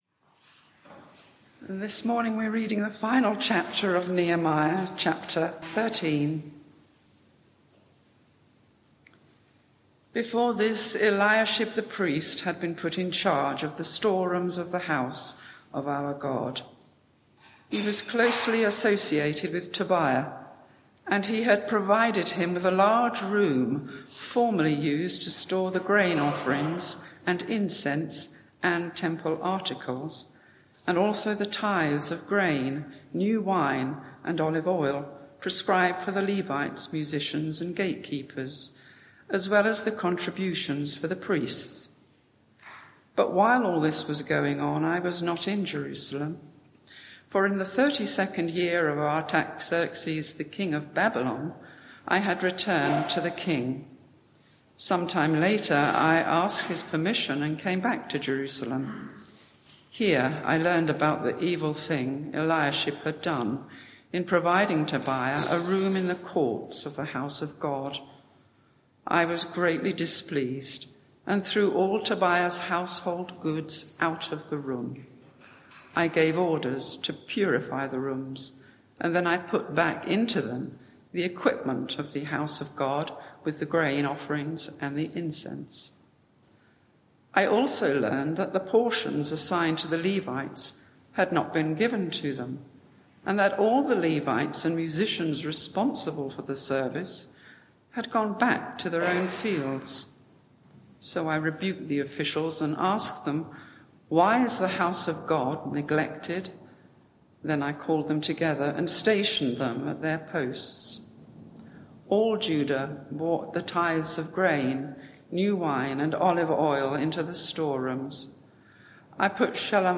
Media for 11am Service on Sun 16th Jun 2024
Sermon (audio)